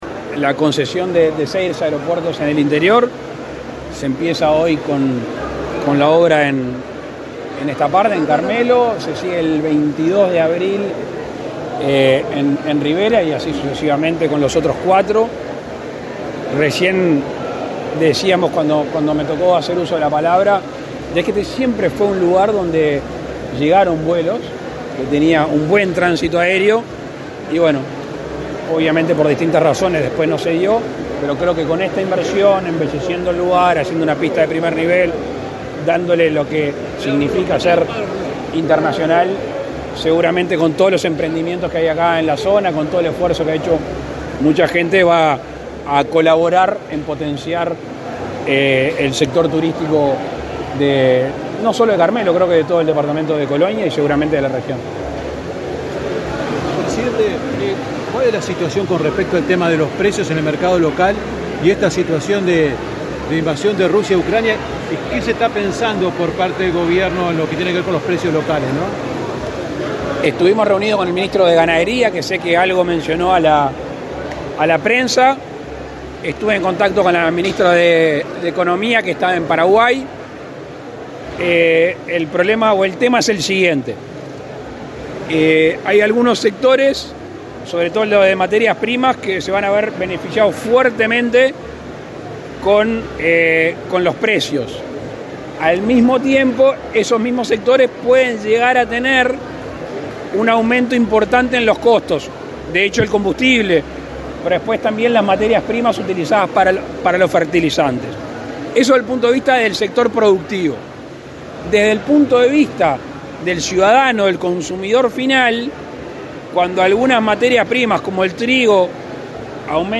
Declaratoria del presidente Lacalle Pou a la prensa